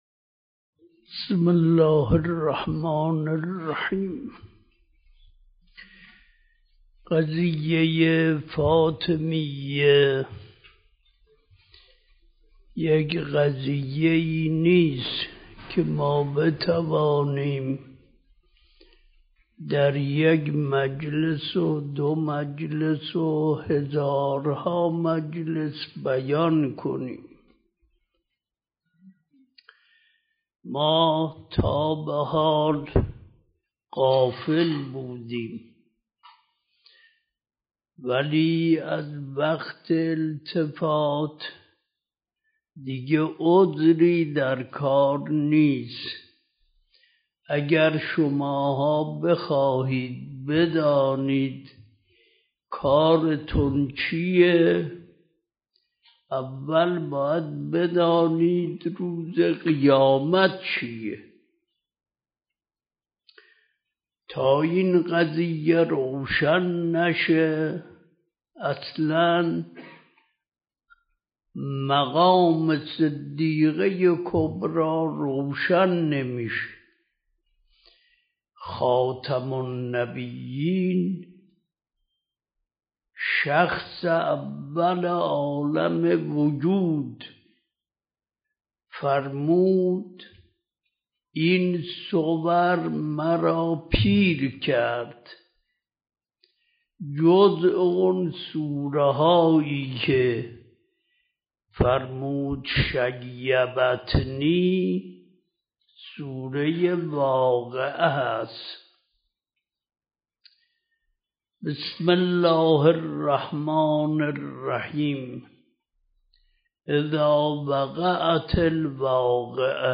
بیانات حضرت آیت الله العظمی وحید خراسانی مد ظله العالی با موضوع “در سوگ شفيعه ی امت” به صورت پخش آنلاین و دانلود فایل صوتی با لینک مستقیم.